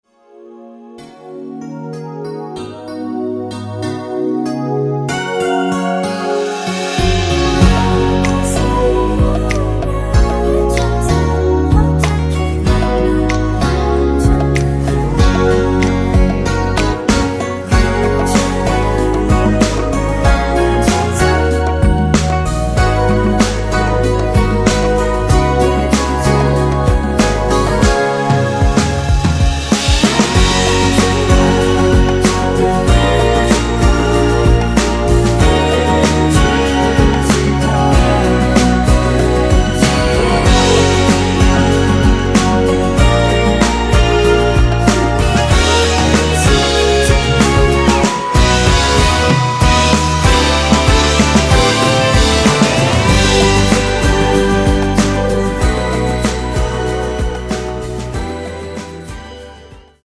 여성분이 부르실수 있는키의 MR입니다.
원키에서(+2)올린 코러스 포함된 MR입니다.(미리듣기 참고)
F#
앞부분30초, 뒷부분30초씩 편집해서 올려 드리고 있습니다.